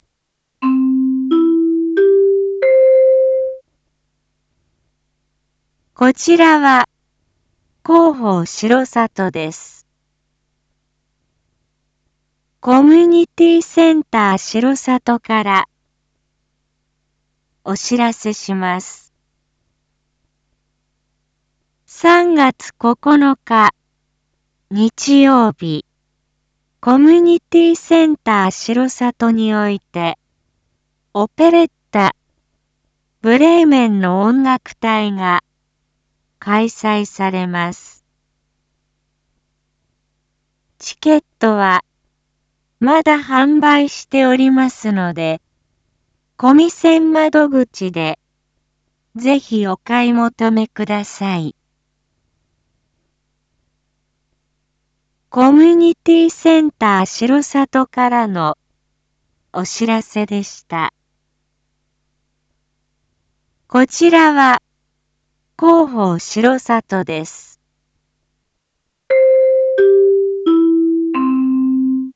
一般放送情報
Back Home 一般放送情報 音声放送 再生 一般放送情報 登録日時：2025-02-21 19:01:13 タイトル：オペレッタ「ブレーメンの音楽隊」の開催について インフォメーション：こちらは広報しろさとです。